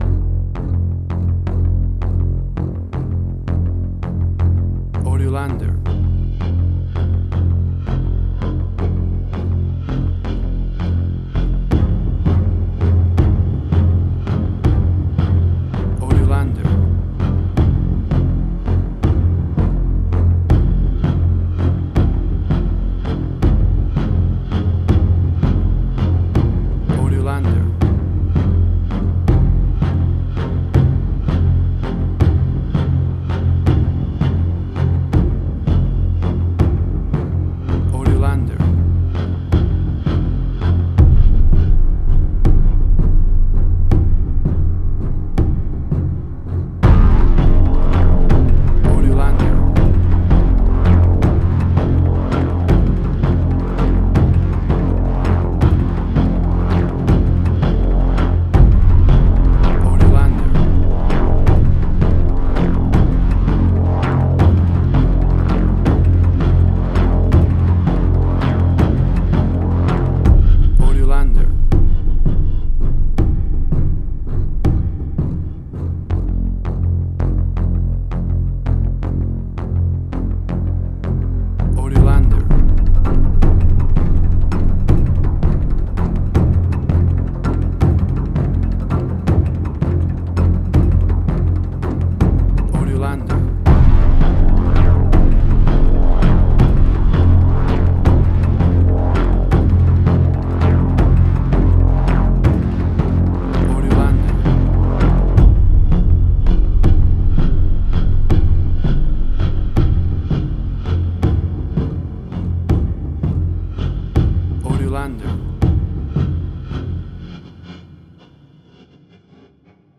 Modern Science Fiction Film, Similar Tron, Legacy Oblivion.
WAV Sample Rate: 16-Bit stereo, 44.1 kHz
Tempo (BPM): 82